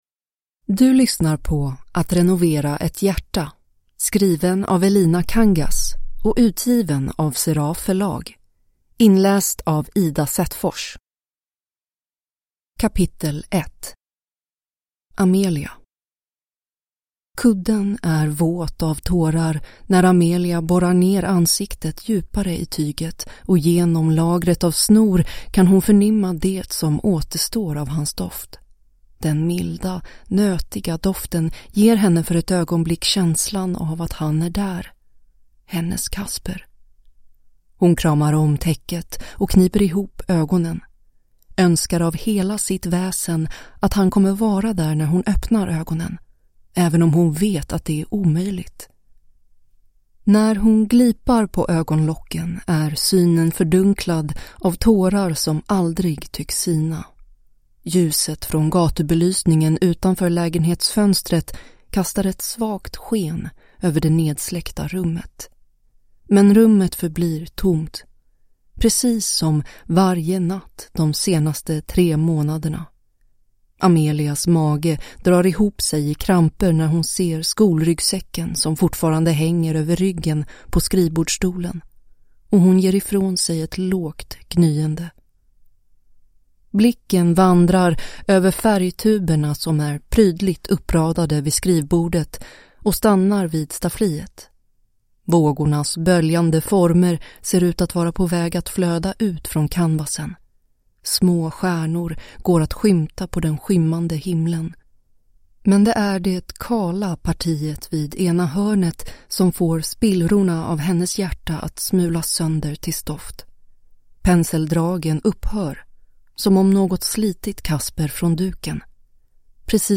Att renovera ett hjärta (ljudbok) av Elina Kangas